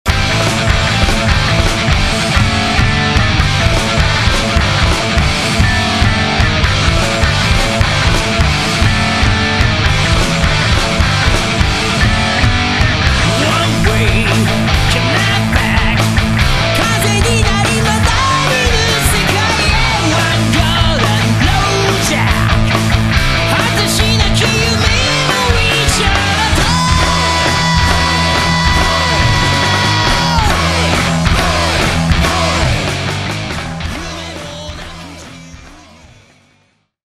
ハード・ロック・サウンドが身上だ。